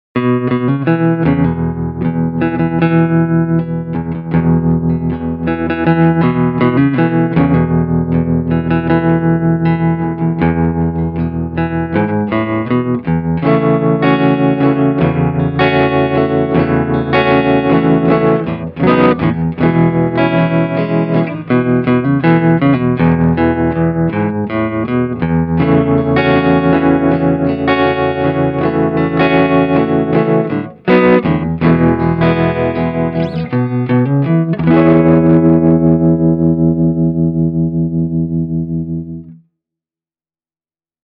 The clean tones are warm and organic, while the tremolo has already become one of my personal favourites.
Reverb and echo have been added at the mixing stage.
Telecaster – ch 2 + tremolo
telecaster-ch-2-tremolo.mp3